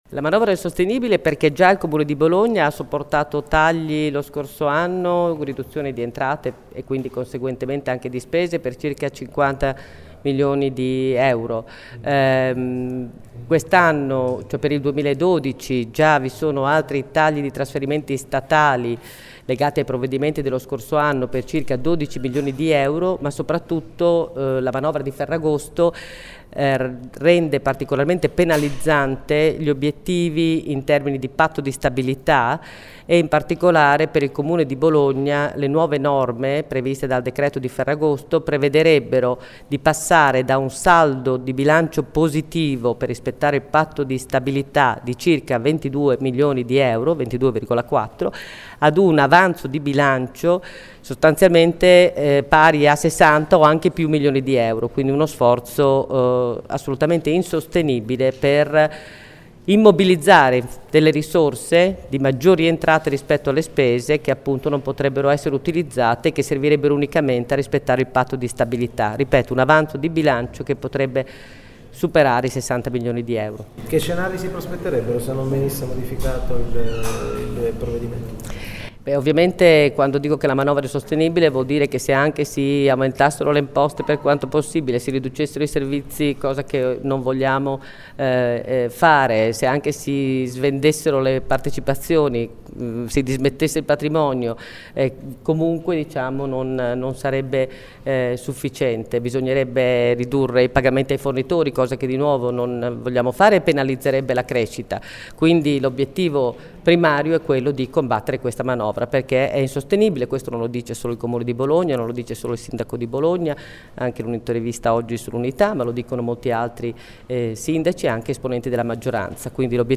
E’ lapidaria Silvia Giannini, vicesindaco con delega al Bilancio di palazzo d’Accursio, che questo pomeriggio ha convocato una conferenza stampa per fare il punto sugli effetti che la manovra presentata dal Governo potrebbe avere sui conti del Comune di Bologna.
Ascolta Giannini